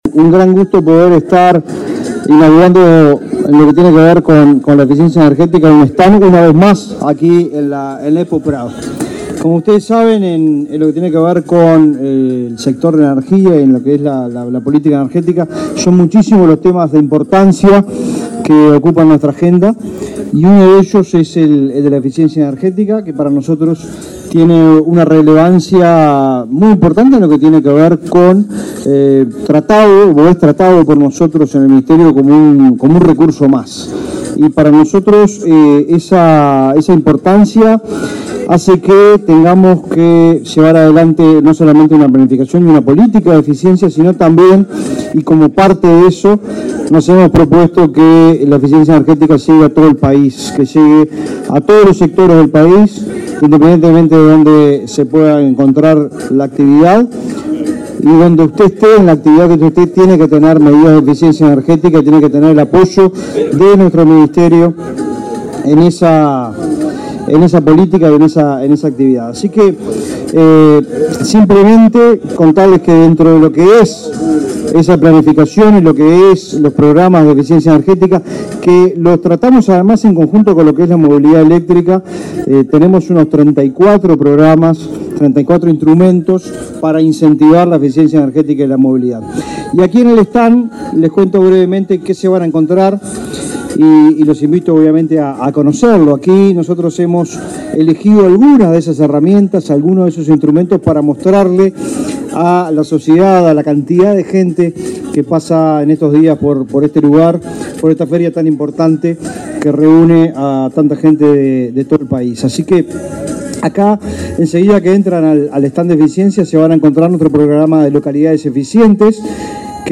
Palabras de las autoridades del MIEM en la Expo Prado